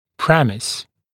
[‘premɪs][‘прэмис]предпосылка, принцип, исходное положение